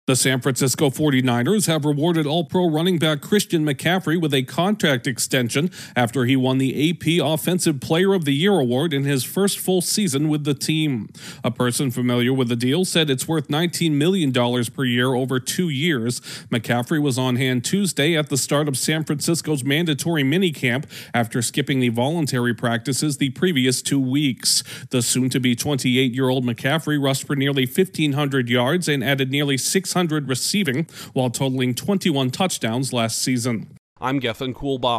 A finalist for the AP NFL MVP Award last season is receiving a significant raise. Correspondent